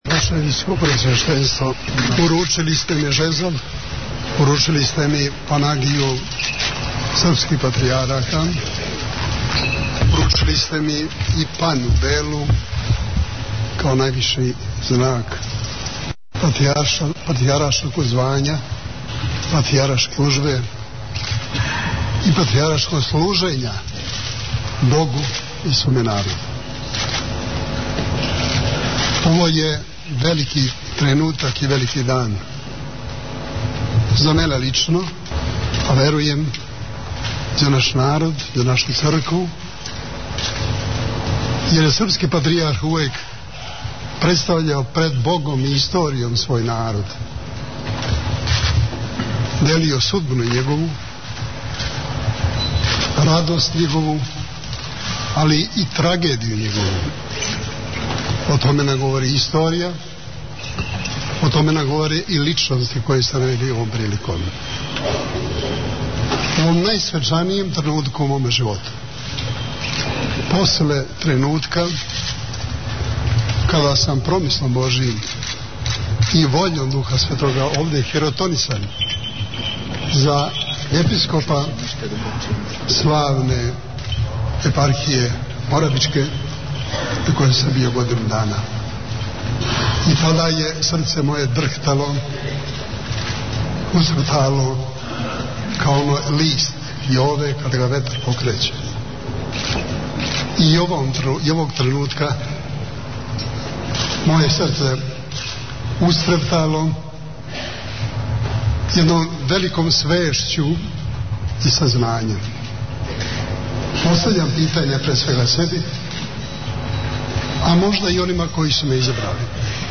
Download the file . 20:32 минута (3.53 МБ) Бесједа Његове Светости Патријарха Српског Г. Иринеја (Гавриловића) са устоличења за 45. Свјатејшег Архиепископа пећког Митрополита београдско - карловачког и Патријарха српског у Саборном Светоархангелском храму у Београду.